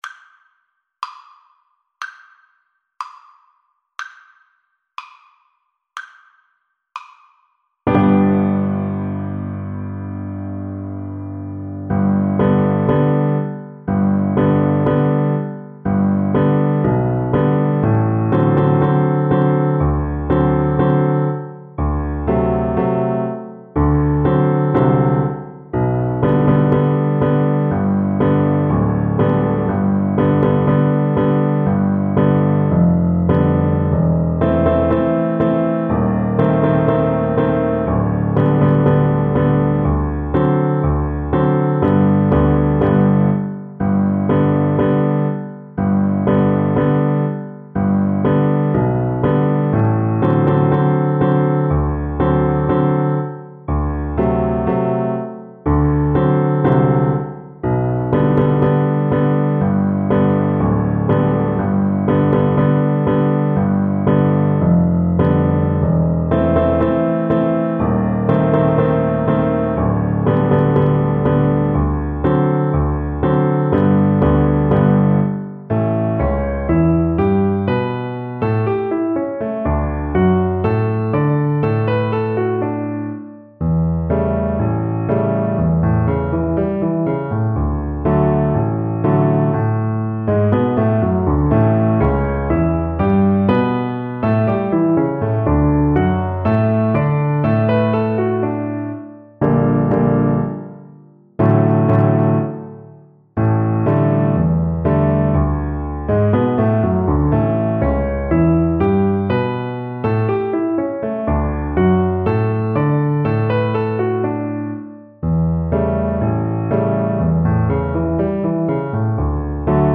2/4 (View more 2/4 Music)
Moderato =c.92
Classical (View more Classical Cello Music)
Brazilian Choro for Cello